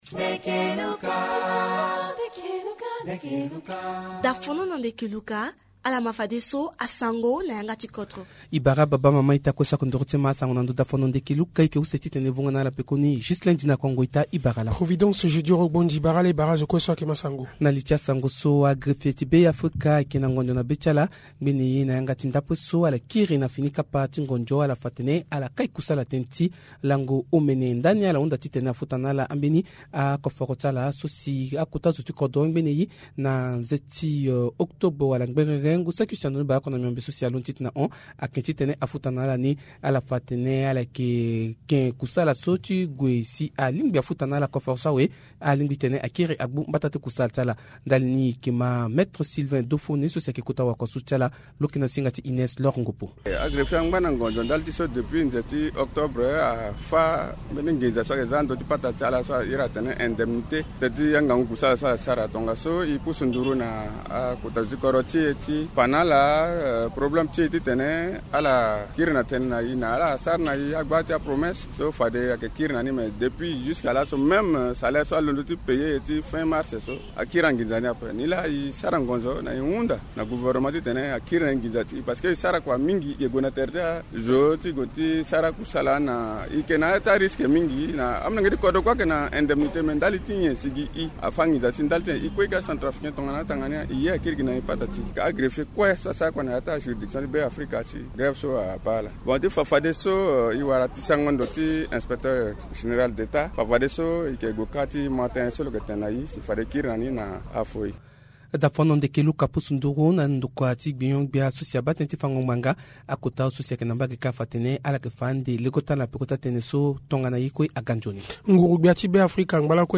Journal Sango